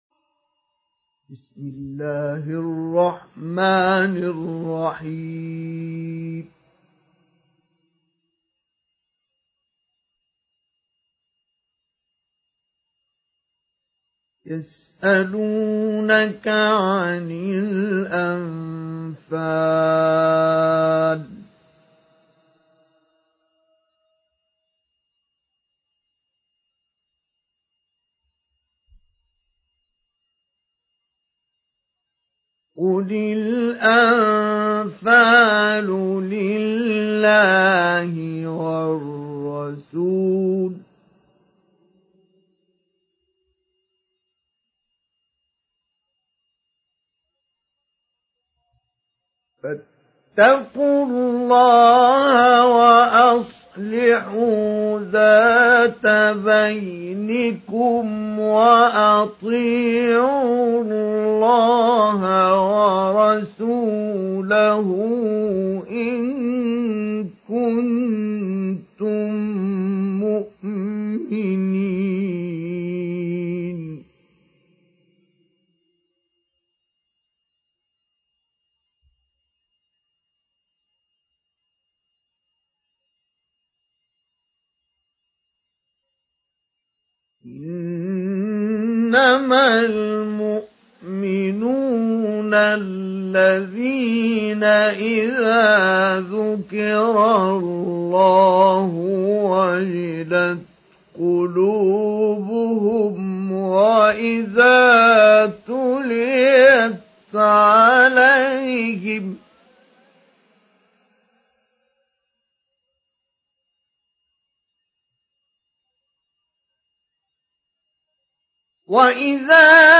Surah Al Anfal mp3 Download Mustafa Ismail Mujawwad (Riwayat Hafs)
Download Surah Al Anfal Mustafa Ismail Mujawwad